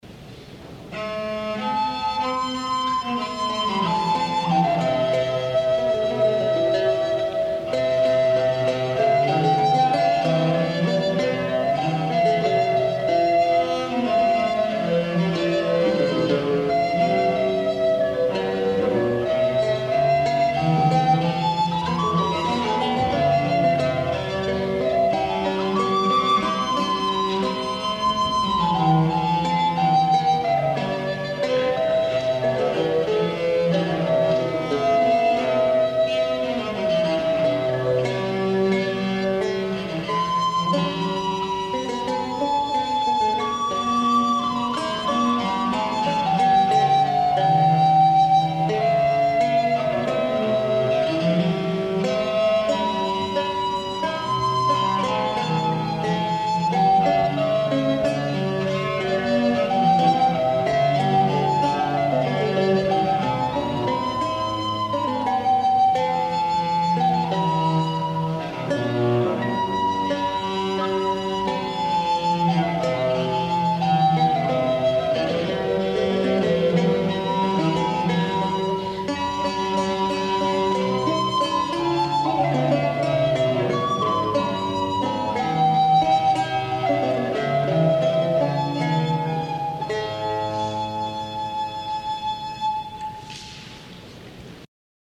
This arrangement of a chanson shows Agricola’s inventive polyphonic interplay of voices (instruments).
recorder
lute
viol